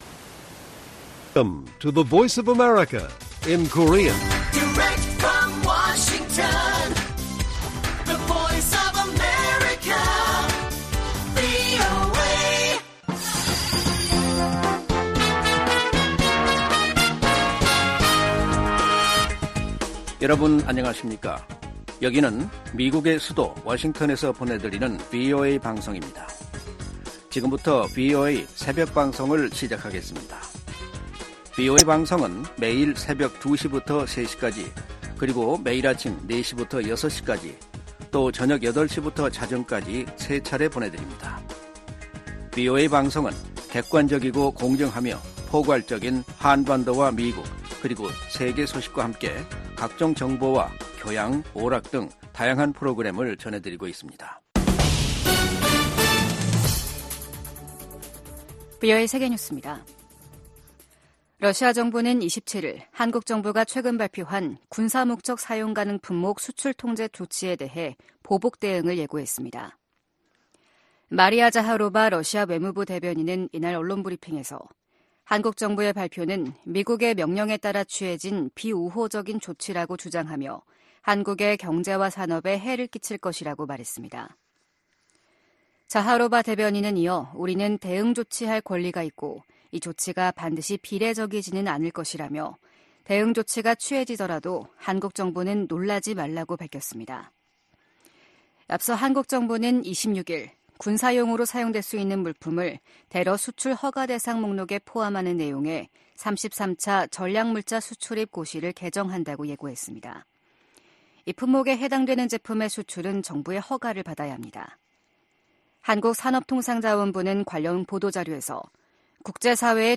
VOA 한국어 '출발 뉴스 쇼', 2023년 12월 28일 방송입니다. 팔레스타인 무장정파 하마스가 북한산 무기를 다량으로 들여왔다고 이스라엘 방위군(IDF) 대변인이 확인했습니다. 올해 미국 의회에서 발의된 한반도 외교안보 관련 법안 가운데 최종 처리된 안건은 12%에 불과했습니다. 북한인권법은 올해도 연장되지 못했습니다.